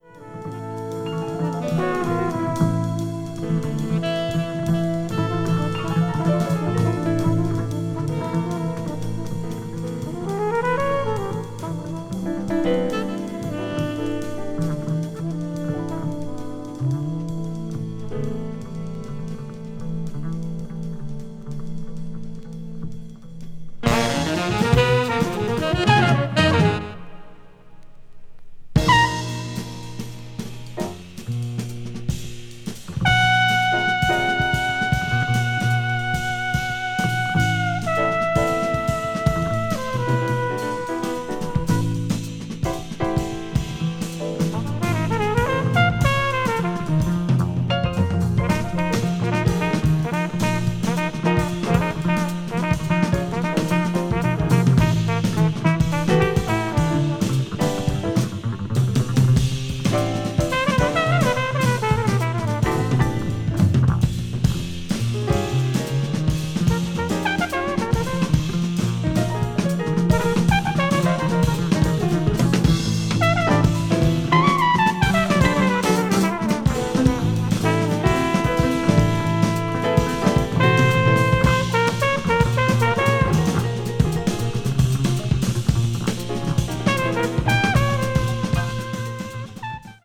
contemporary jazz   modal jazz   post bop   spiritual jazz